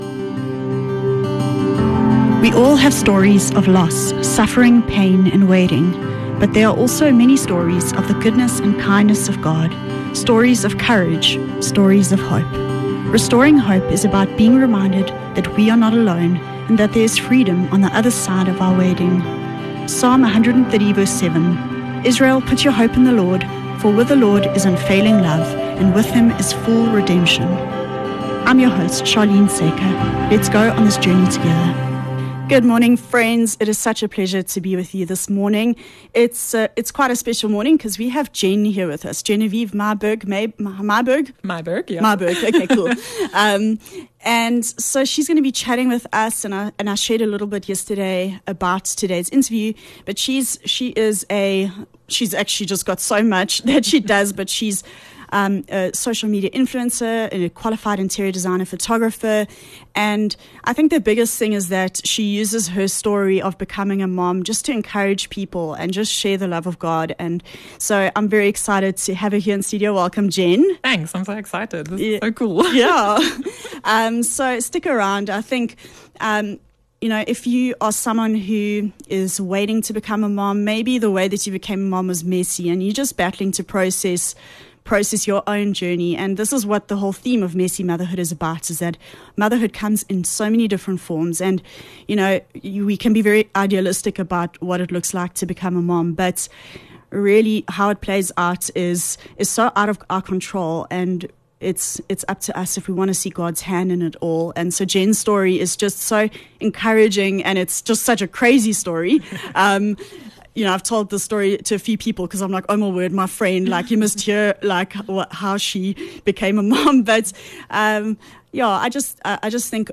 14 Mar Messy Motherhood - Interview